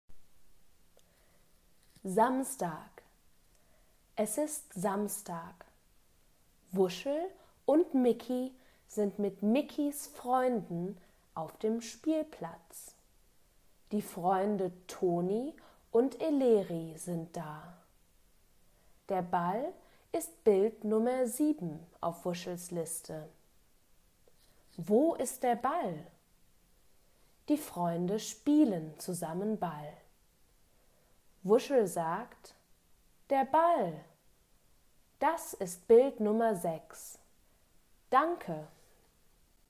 Gelesener Text: Begleitheft S.38(MP3, 635 KB)